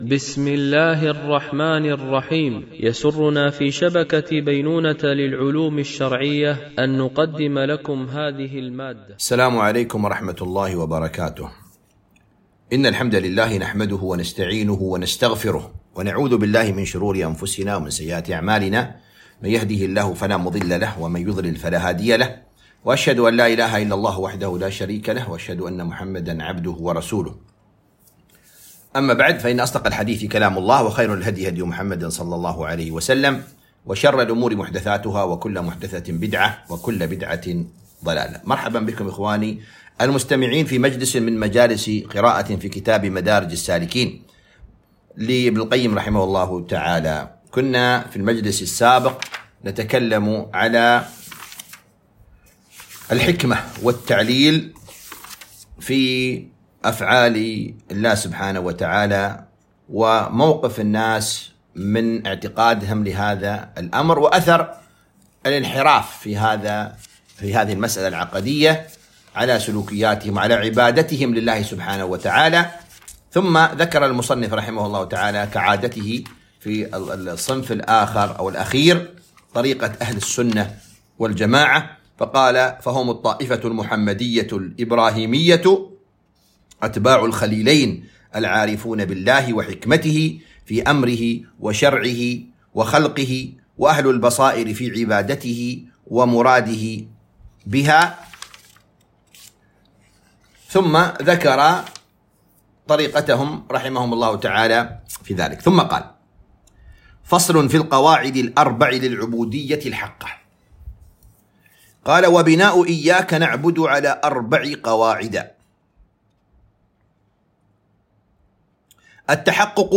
قراءة من كتاب مدارج السالكين - الدرس 14